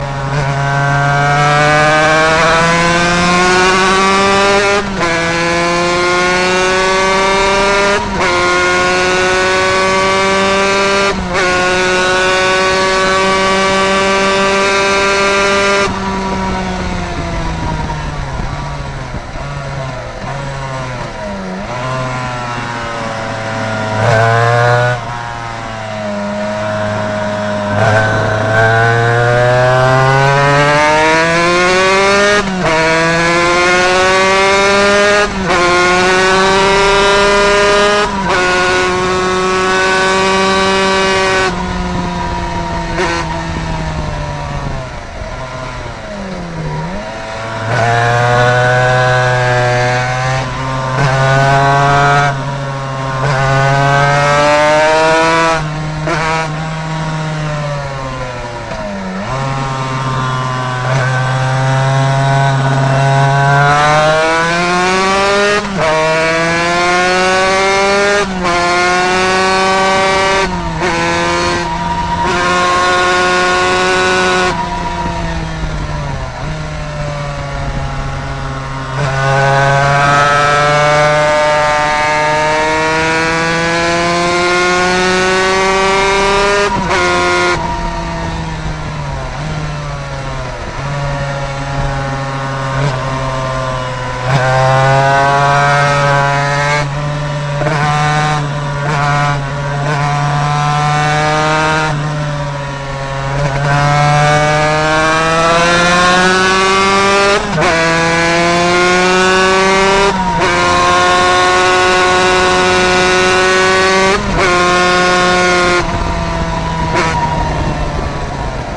zuviel windgeräusche. dann nimm lieber meine aufnahme aus most.
mit tyga...
Noch ne Frage: Du fährst ohne Zwischengas beim Runterschalten, warum?